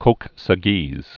(kōksə-gēz)